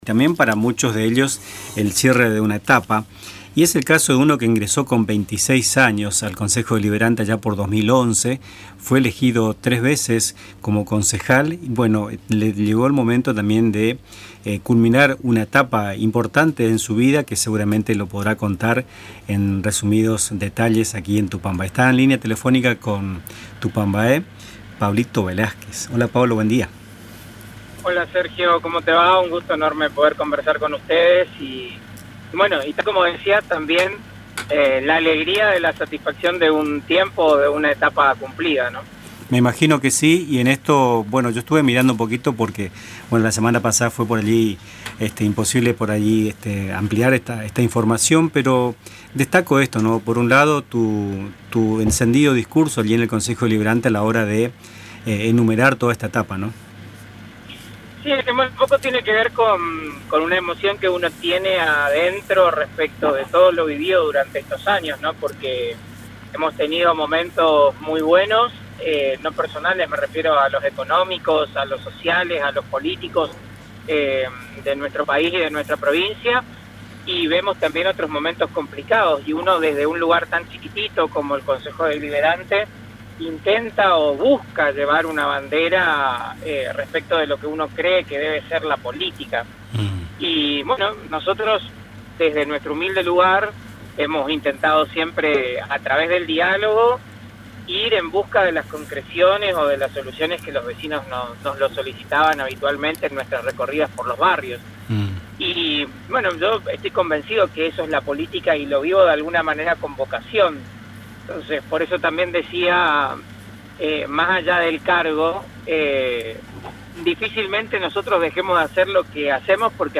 Velázquez, quien ingresó al Concejo Deliberante con apenas 26 años, compartió este martes en el programa Nuestras Mañanas sus reflexiones sobre una trayectoria de 14 años dedicada a la política local.